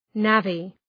Shkrimi fonetik {‘nævı}